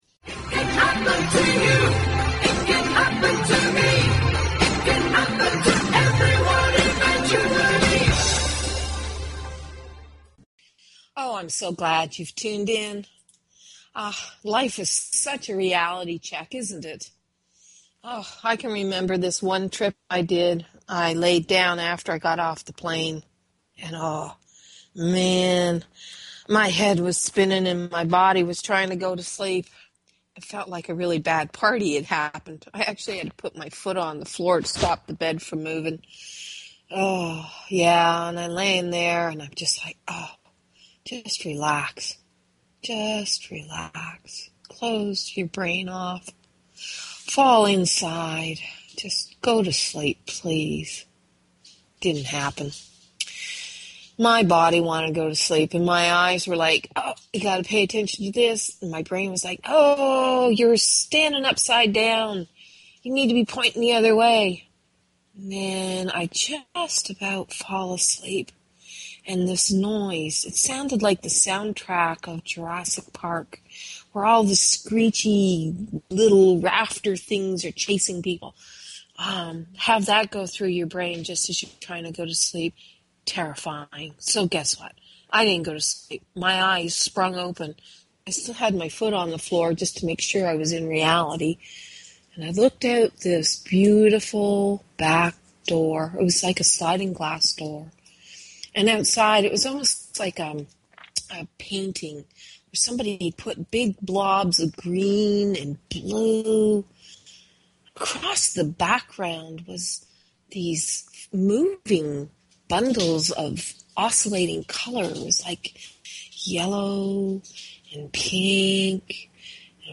Talk Show Episode, Audio Podcast, eSO_Logic_Radio and Courtesy of BBS Radio on , show guests , about , categorized as